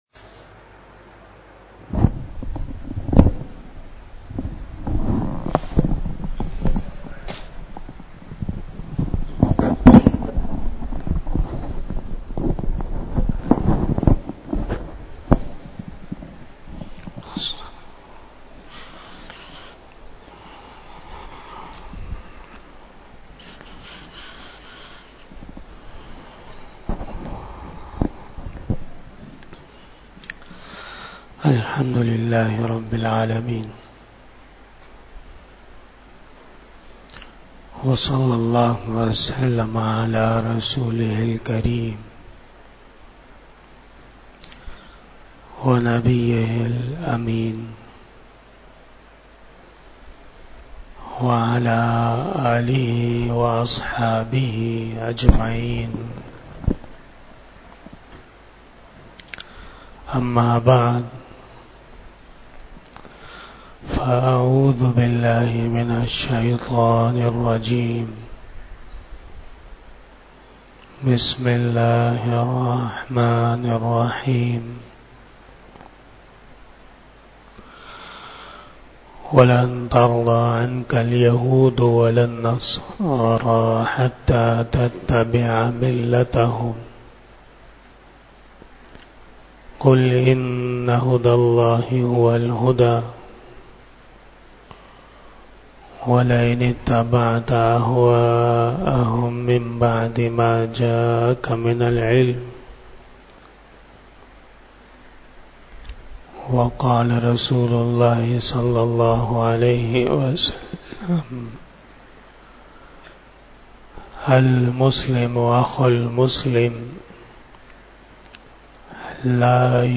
44 Bayan E Jummah byan 03 November 2023 (18 Rabi Us Sani 1445 HJ)
Khitab-e-Jummah